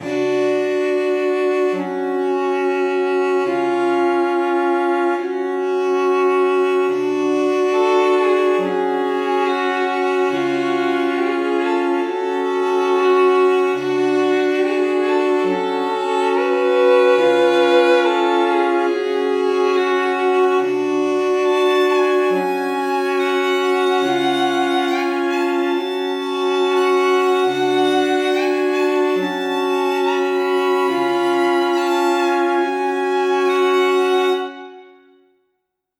Categories: Melodies
20 High-Quality String Melodies Made Completely From Scratch.
Lonely-Island_140BPM_Fmin.wav